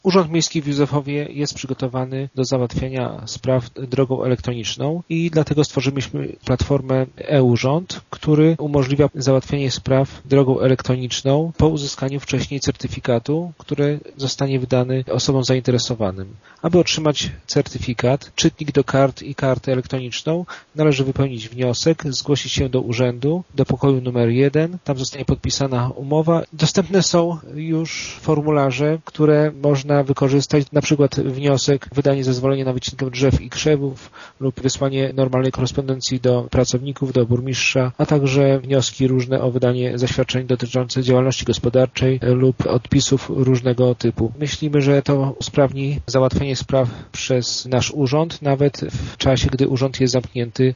Wcześniej jednaj muszą otrzymać odpowiedni certyfikat, który za darmo można odebrać w urzędzie – mówi zastępca burmistrza Ireneusz Wilczyński: